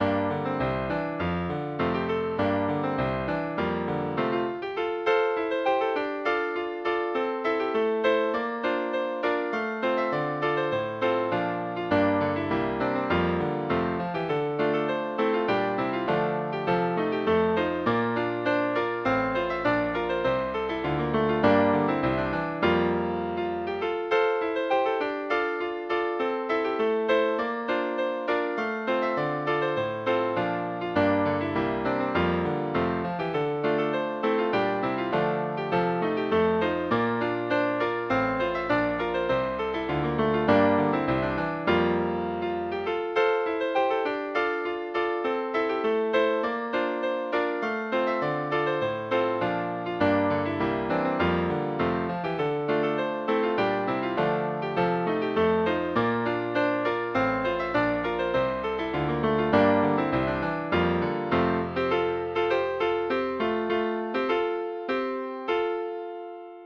This is an old Gaelic tune from The Celtic Lyre by Henry Whyte.
sadami.mid.ogg